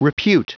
Prononciation du mot repute en anglais (fichier audio)
Prononciation du mot : repute